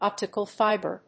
アクセントóptical fíber